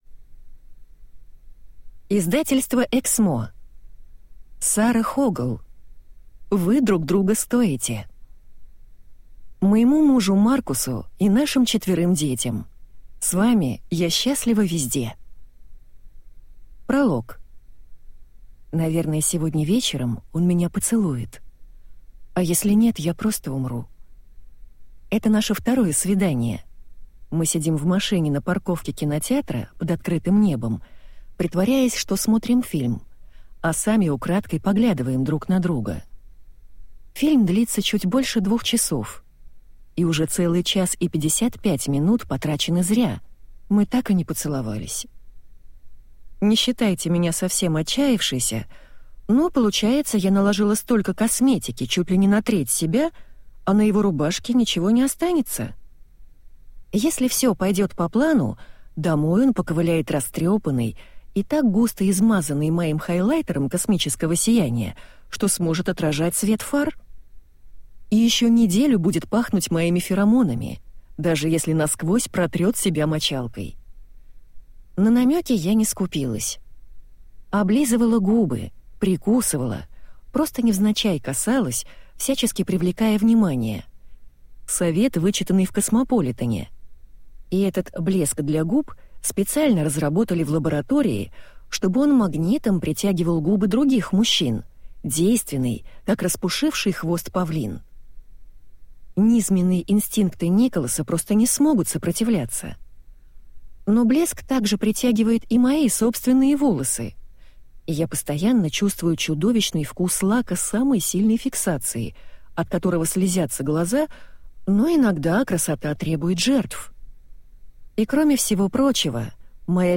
Аудиокнига Вы друг друга стоите | Библиотека аудиокниг